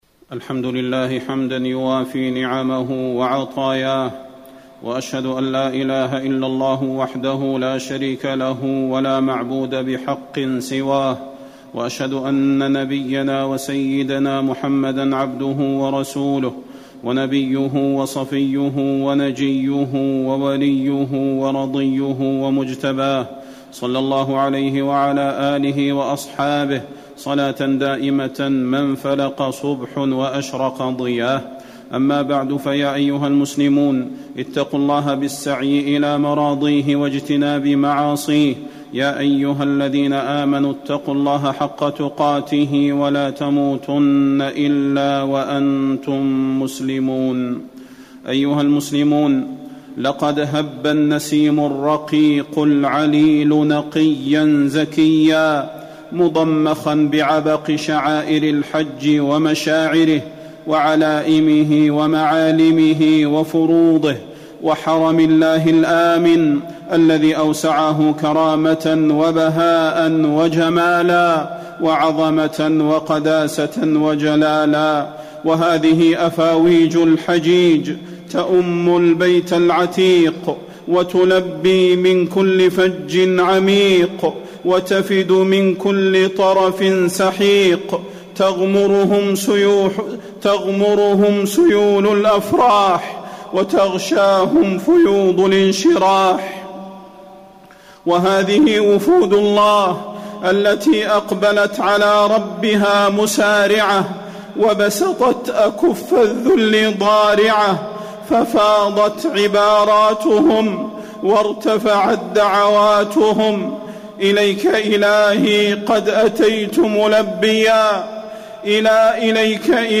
تاريخ النشر ٦ ذو الحجة ١٤٣٩ هـ المكان: المسجد النبوي الشيخ: فضيلة الشيخ د. صلاح بن محمد البدير فضيلة الشيخ د. صلاح بن محمد البدير وصايا للحجاج والمعتمرين